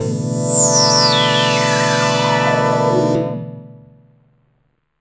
46PAD 01  -R.wav